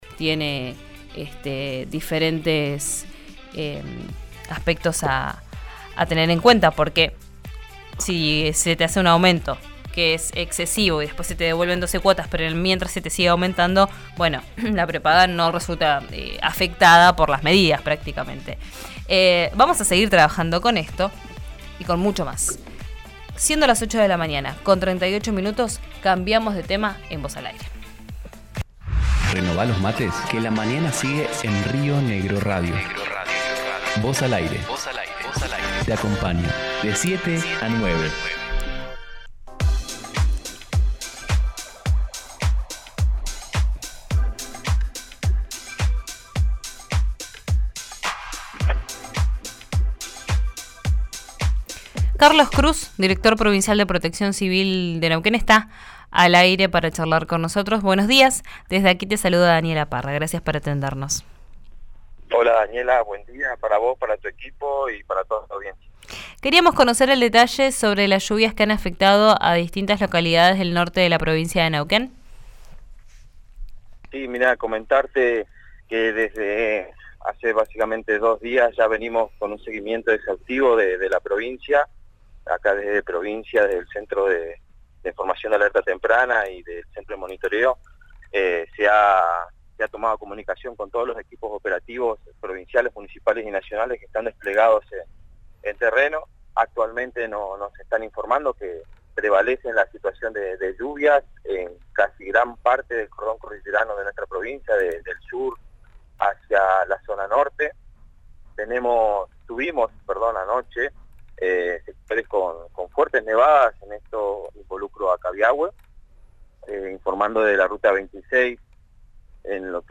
Escuchá al director Provincial de Protección Civil de Neuquén en RÍO NEGRO RADIO